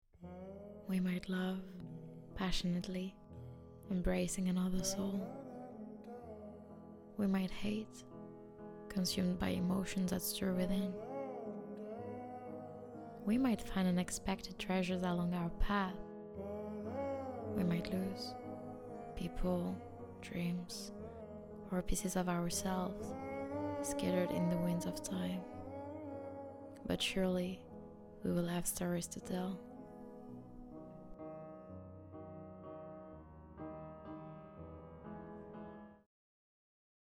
Bandes-son
Voice over - English
- Mezzo-soprano